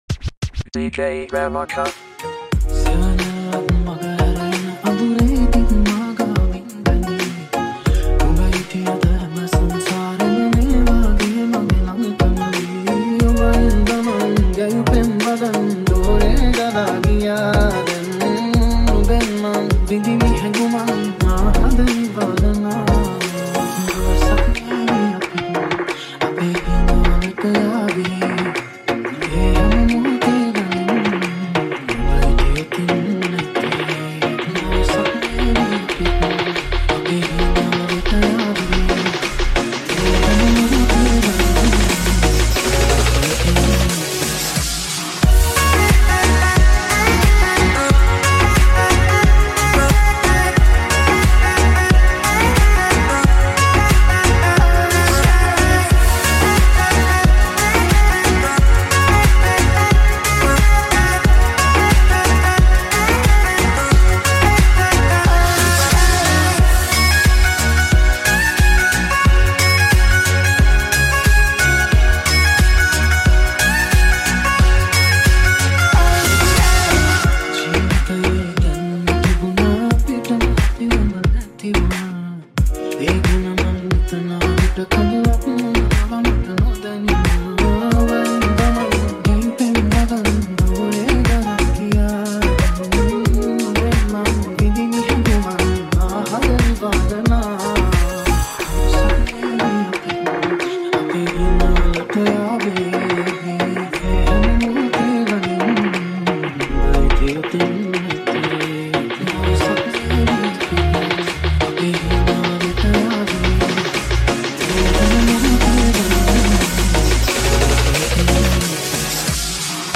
Tropical House Remix